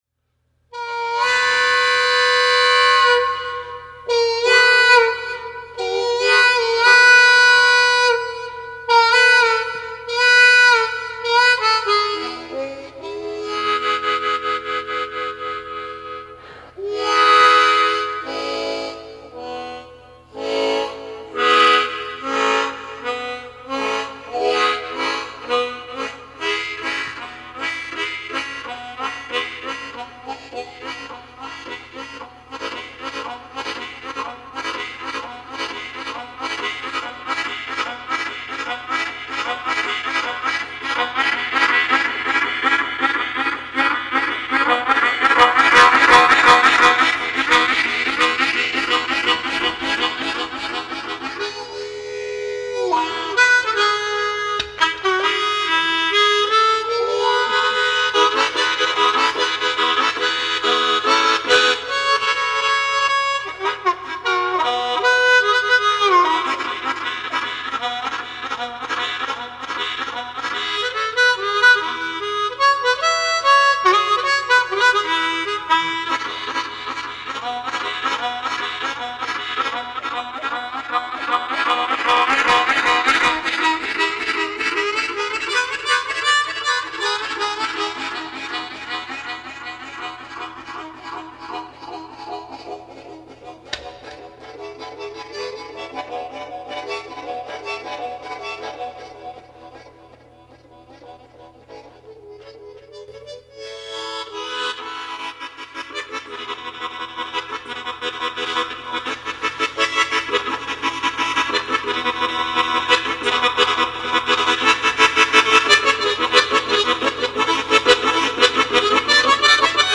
Tremolo